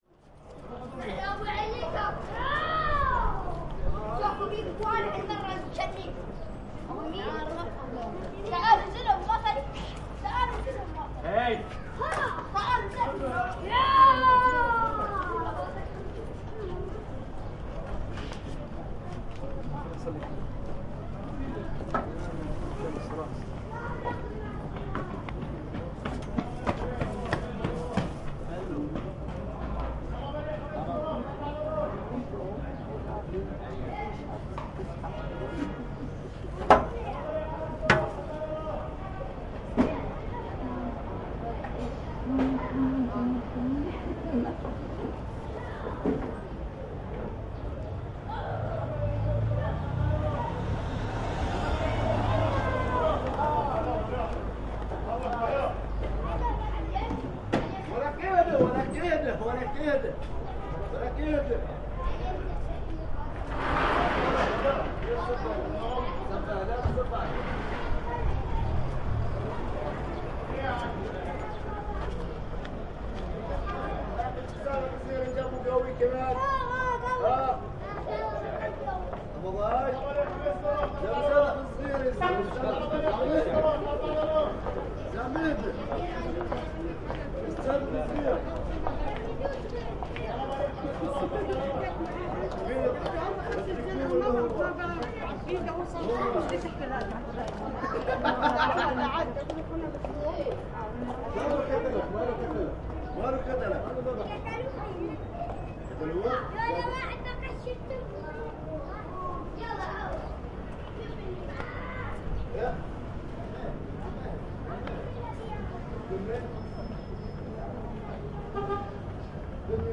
描述：在伊尔比德市中心的Dar AsSaraya博物馆入口处度过了一个刮风的下午。使用Zoom H4N录制。
声道立体声